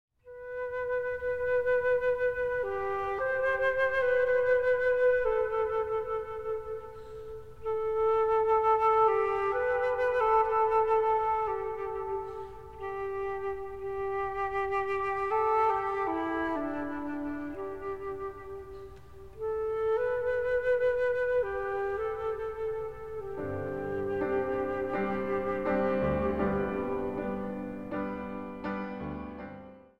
orgel
piano
fluit
trompet.
Zang | Gemengd koor